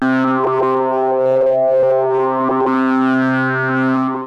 TALKING OSC 3.wav